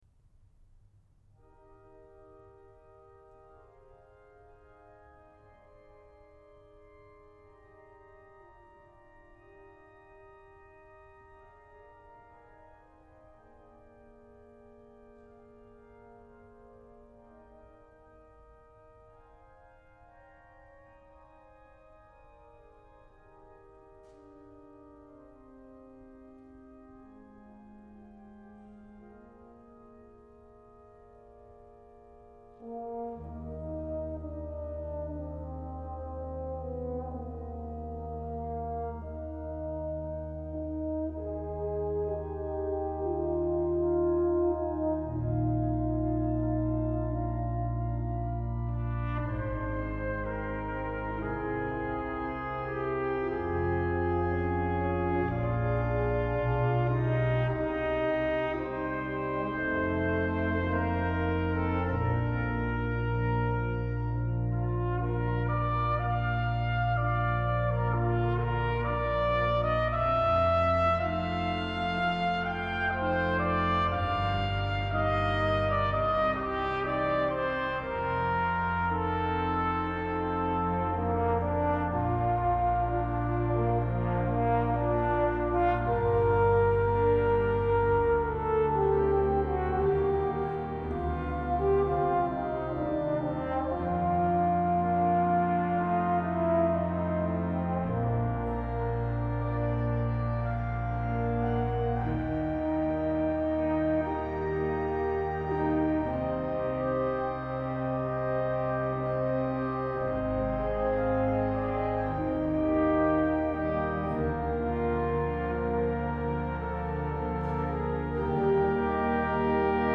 Für Brass Quintett und Orgel
Ensemblemusik für Blechbläser-Quintett